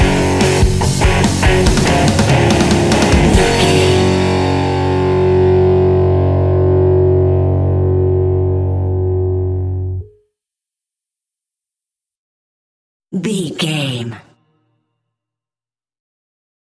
Epic / Action
Fast paced
Ionian/Major
F#
hard rock
blues rock
Rock Bass
heavy drums
distorted guitars
hammond organ